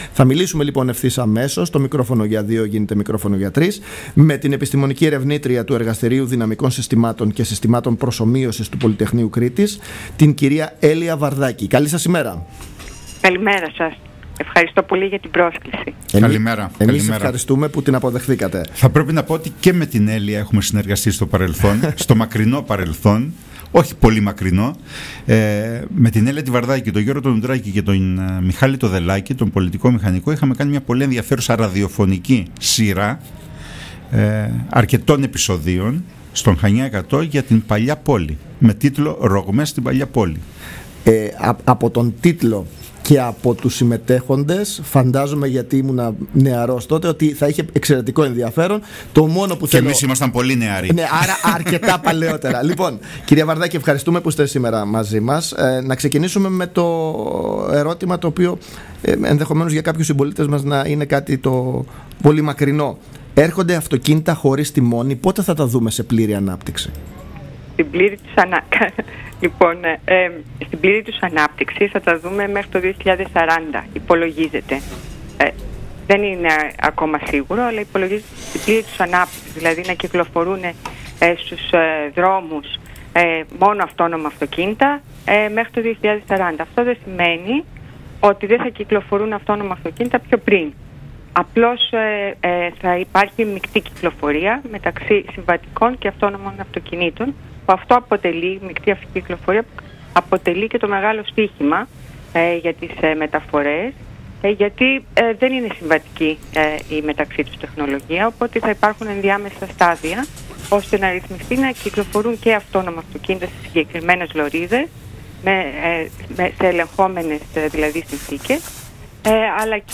has provided on 5 February 2021 an interview to the local radio station ANTENA 97.1 (WESTERN CRETE) on social implications of autonomous vehicles.